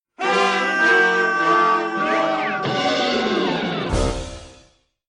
game_over.mp3